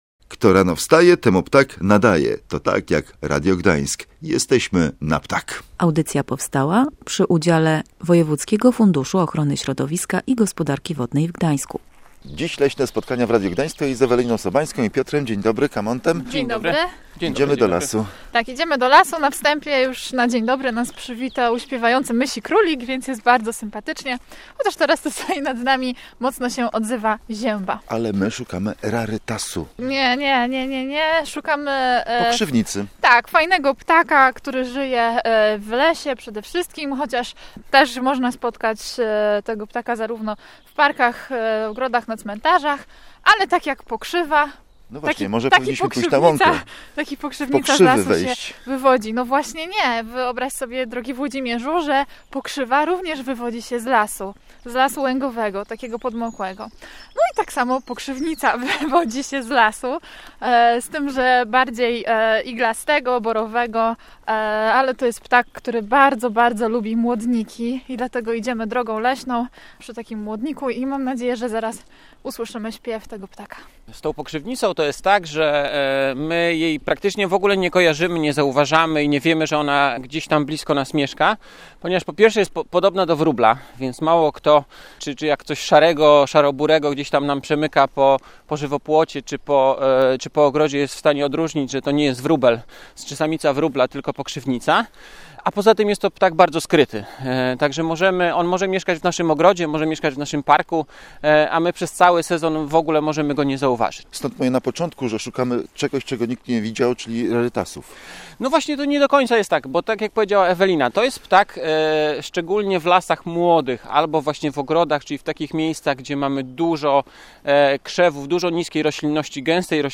Myślicie, że to wróbelek, a ten ptak nie ćwierka, raczej pogwizduje.
Najłatwiej pokrzywnice odnaleźć po głosie. Ekipie Ptasiego Radia Gdańsk udało się bez problemu odszukać pokrzywnice w oliwskich lasach.